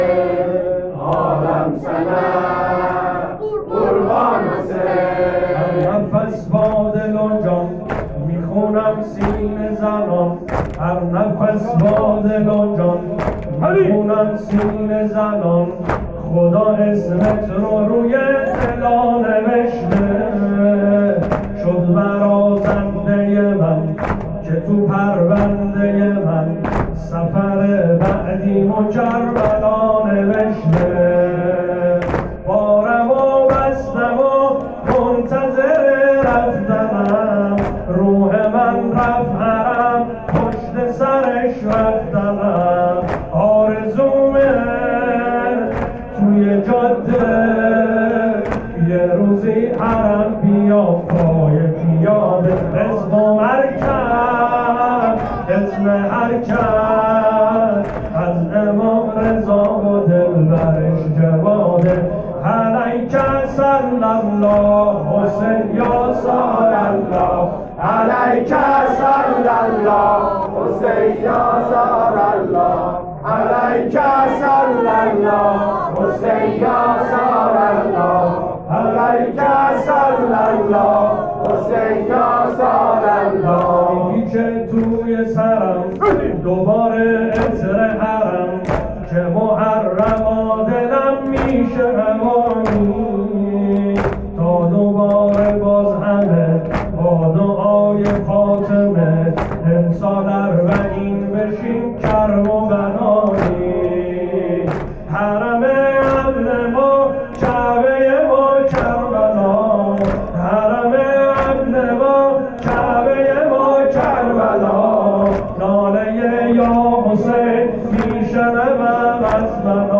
واحد شب عاشورا